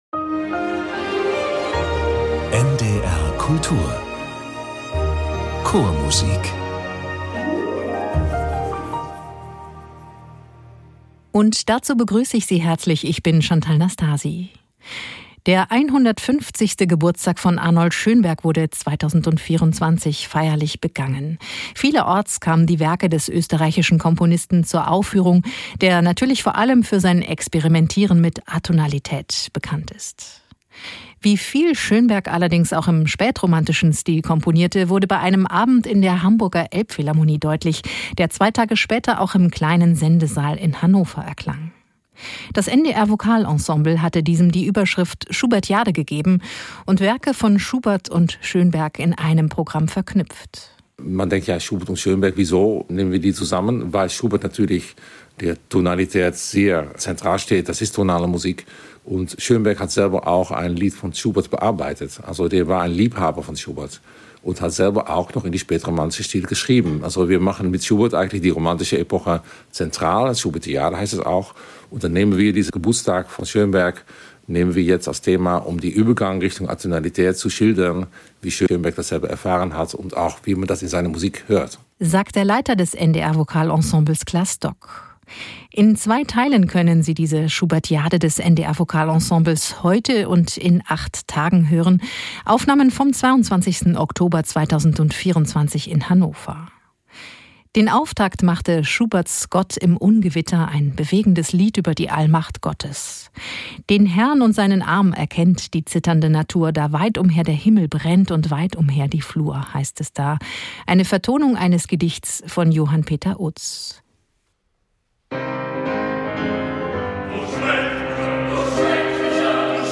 Chormusik aus allen Jahrhunderten, aus allen Ländern und Zeiten und Chöre aus aller Welt.